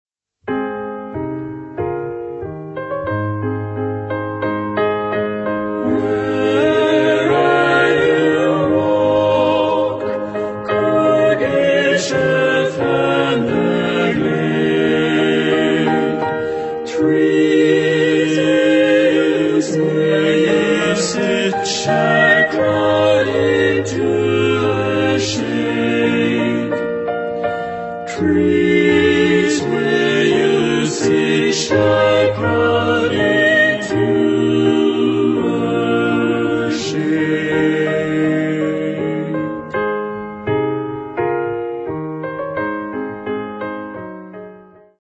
Genre-Style-Form: Secular ; Baroque ; Aria ; Choral setting
Mood of the piece: broad
Type of Choir: SATB  (4 mixed voices )
Instrumentation: Piano  (1 instrumental part(s))
Tonality: A flat major